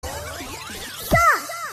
Звуки со словом Всё
Звук человеческого голоса произносящего слово всё